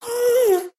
moan3.mp3